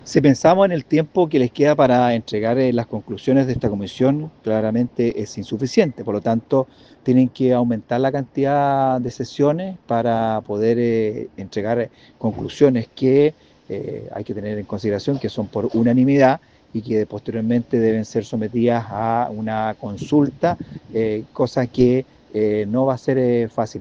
El diputado de Renovación Nacional, Jorge Rathgeb, dijo que la instancia debe aumentar la cantidad de sesiones para elaborar las propuestas que deben entregar al presidente Boric.